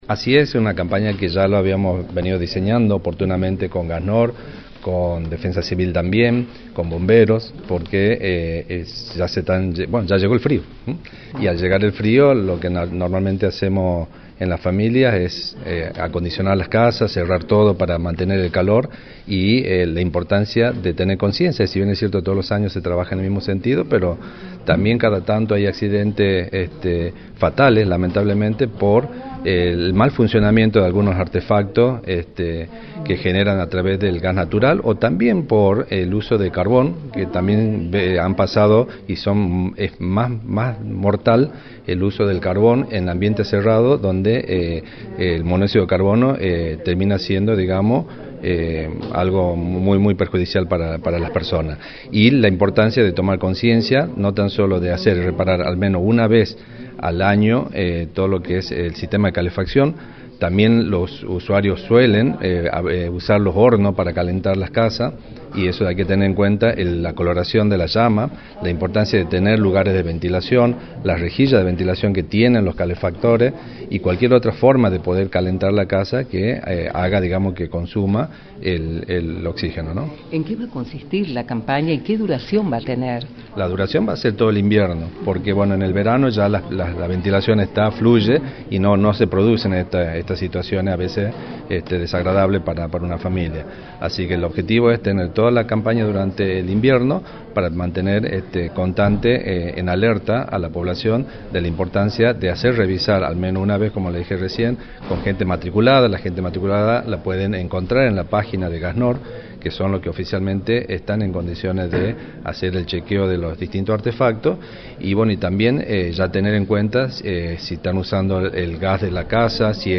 “Al llegar el frío, lo que hacemos es acondicionar las casas, cerrar todo para mantener el calor y por esto hay que tener conciencia, cada tanto hay accidentes fatales por el mal funcionamiento de aparatos que se generan a través del gas natural” señaló el Ministro de Obras y Servicios Públicos, Fabián Soria, en el móvil de Radio del Plata Tucumán, por la 93.9.